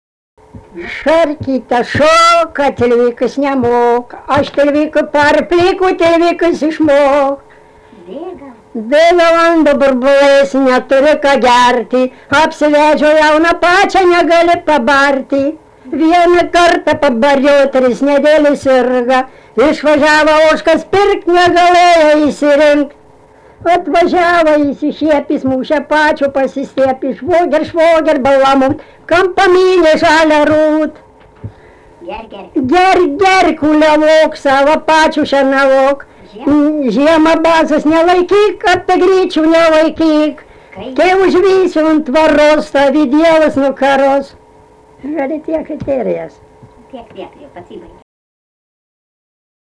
Dalykas, tema daina
Erdvinė aprėptis Baibokai
Atlikimo pubūdis vokalinis